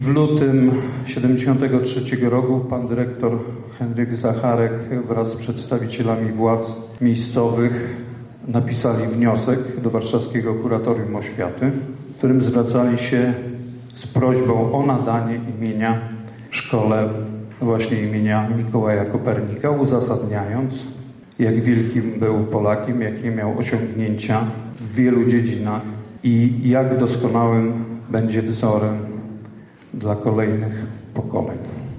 Tak Szkoła Podstawowa w Goworowie obchodziła wczoraj swój złoty jubileusz 50-lecia nadania imienia Mikołaja Kopernika.
Historia nadania imienia jest nam doskonale znana kontynuował Piotr Kosiorek, Wójt gminy Goworowo: